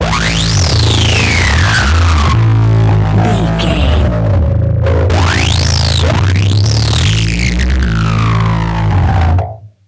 Short musical SFX for videos and games.,
Sound Effects
Ionian/Major
aggressive
bright
epic
intense
driving
bouncy
energetic
funky
heavy